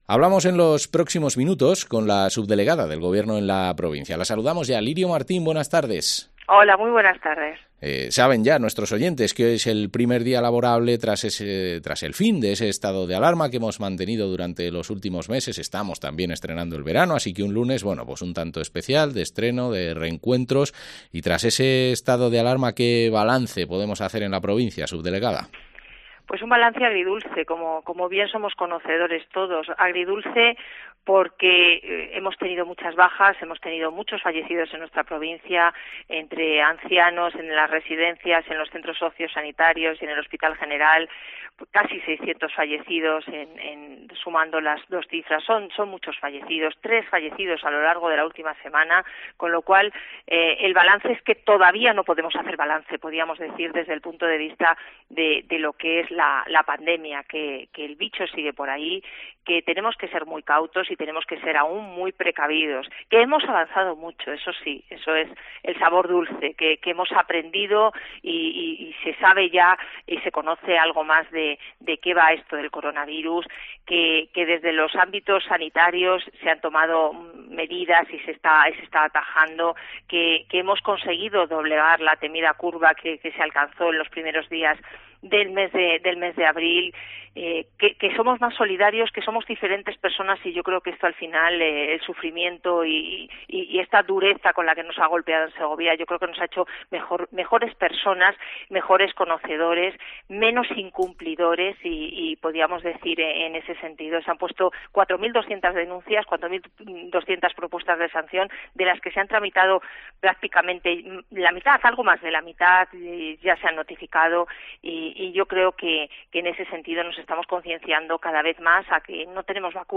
Entrevista a la subdelegada del Gobierno, Lirio Martín